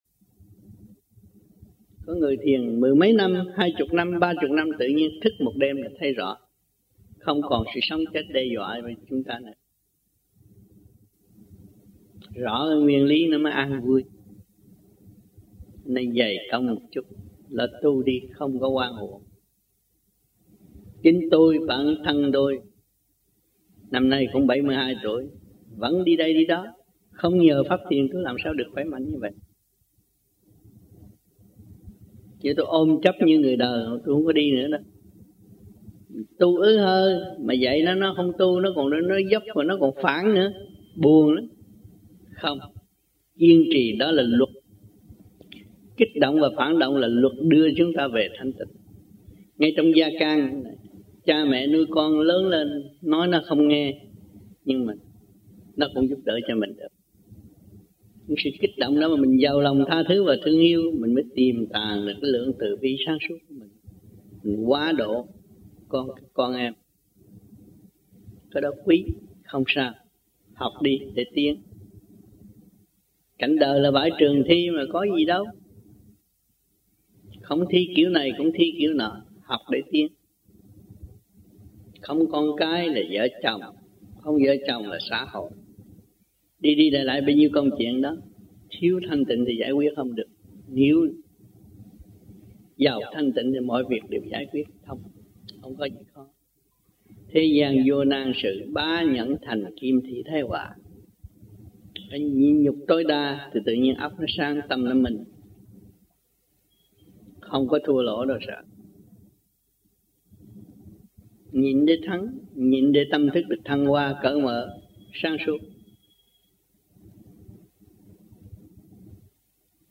1994 Khóa Học Tại Vancouver